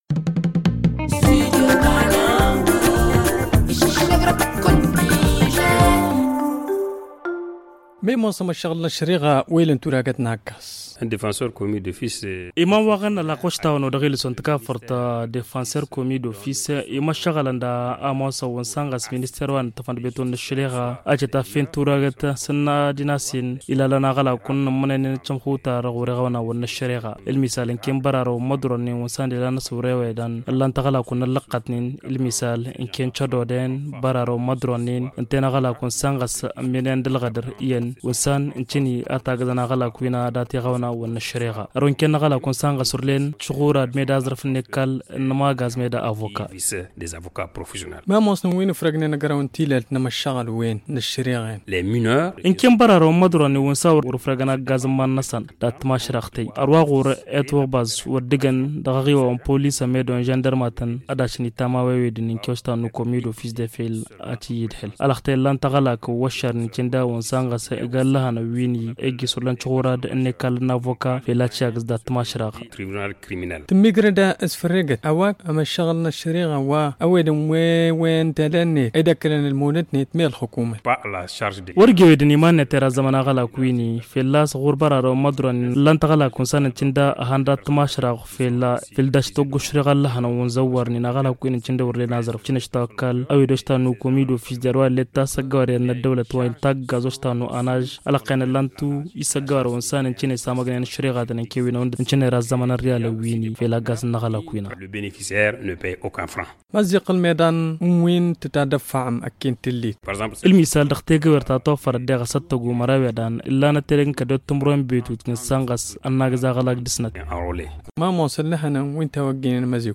Le magazine en tamasheq